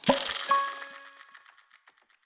Special & Powerup (17).wav